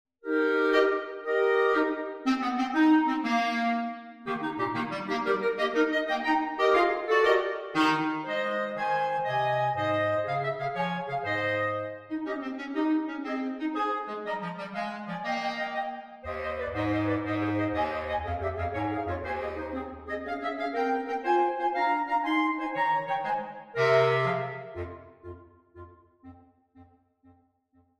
Clarinet Trio (3 Cl or 2 Cl+BassCl)
Four lively medleys